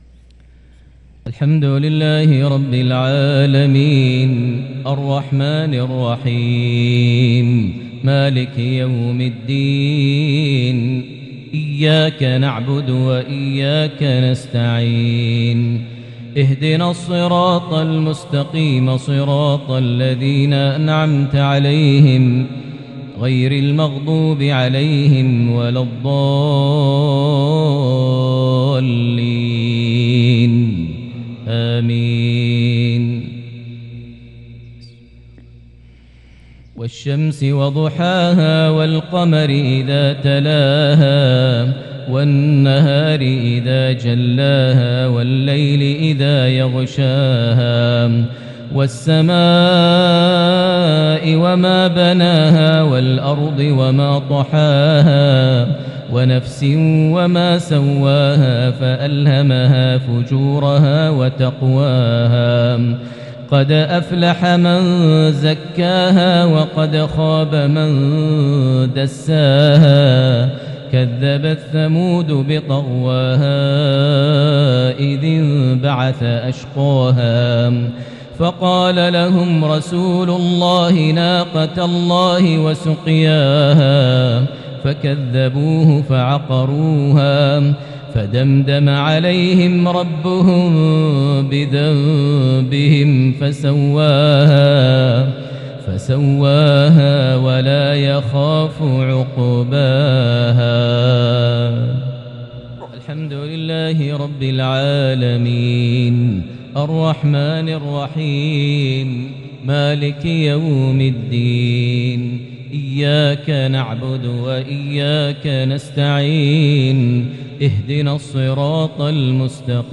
salah_jumua_prayer from 1-4-2022 Surah Ash-Shams + Surah Al-Lail > 1443 H > Prayers - Maher Almuaiqly Recitations